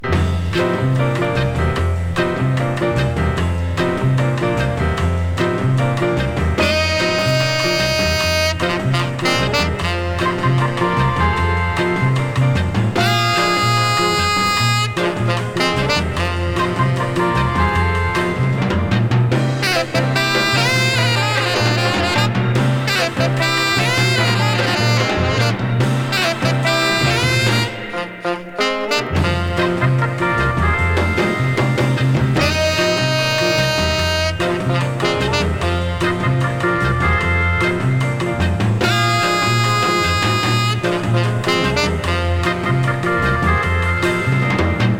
こちらは彼の死後に定番楽曲を集めた1枚で、小気味良い演奏が目白押しで、楽しい好盤。
Soul, Rhythm & Blues　USA　12inchレコード　33rpm　Stereo